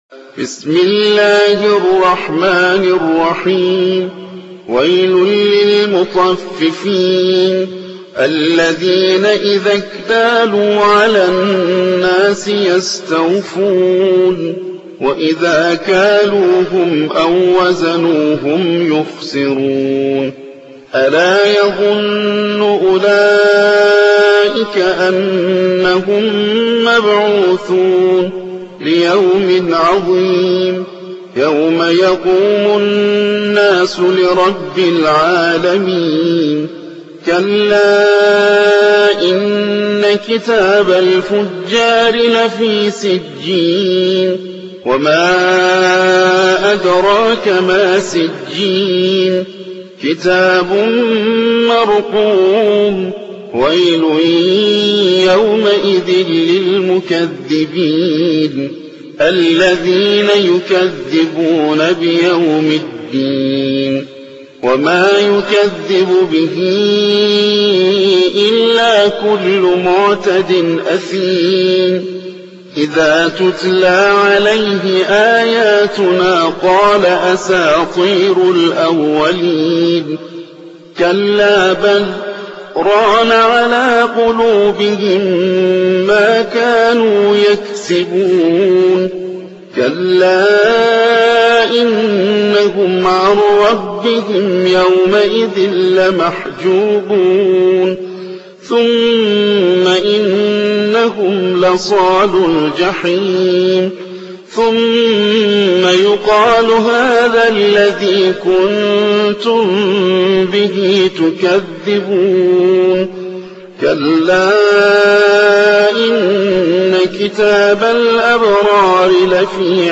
83. سورة المطففين / القارئ